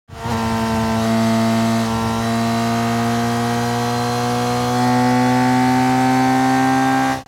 جلوه های صوتی
دانلود آهنگ موتور 1 از افکت صوتی حمل و نقل
دانلود صدای موتور 1 از ساعد نیوز با لینک مستقیم و کیفیت بالا
برچسب: دانلود آهنگ های افکت صوتی حمل و نقل دانلود آلبوم صدای موتورسیکلت از افکت صوتی حمل و نقل